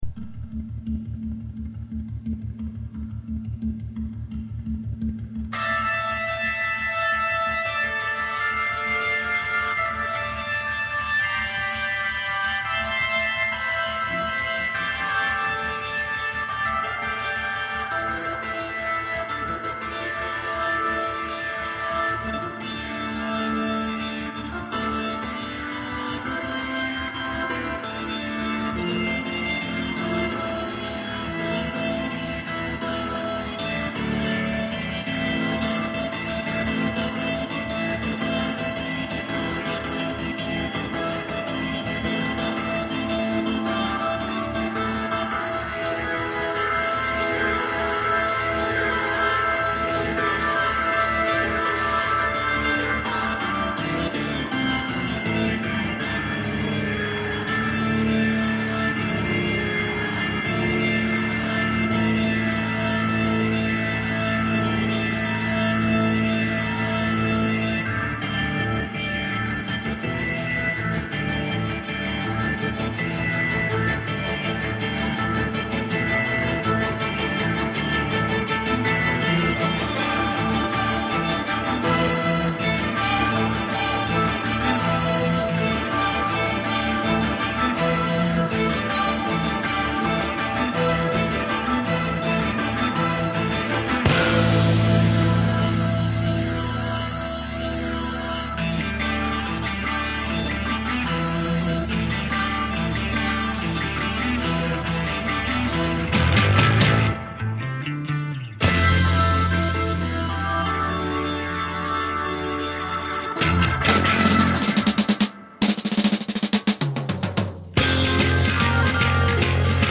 snarling bass sound